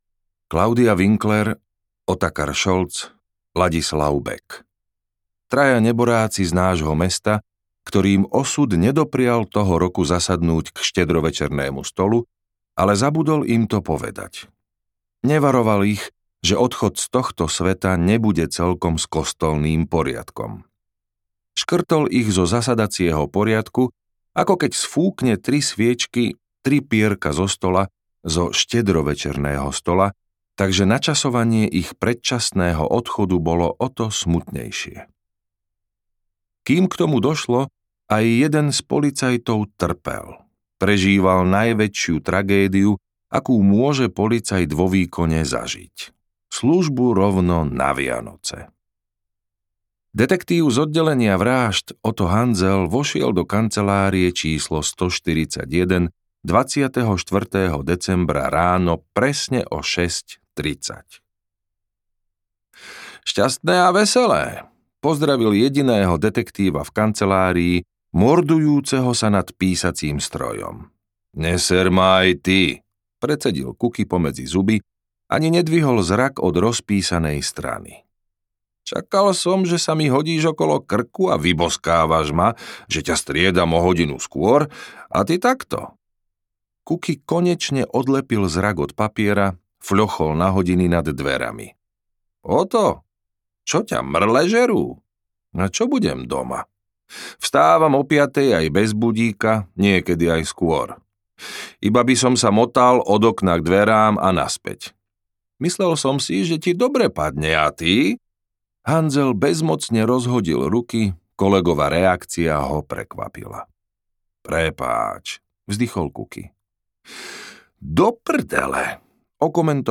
Vianočná nádielka audiokniha
Ukázka z knihy
vianocna-nadielka-audiokniha